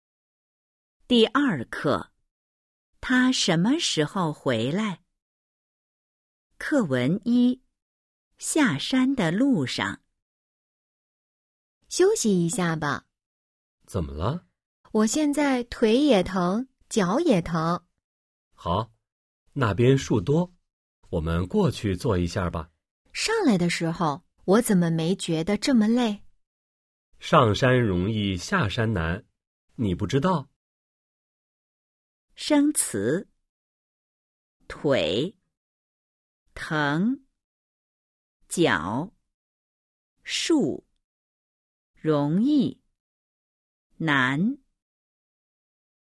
Bài hội thoại 1:  🔊 下山的路上 – Trên đường xuống núi  💿 02-01